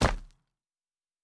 Stone 04.wav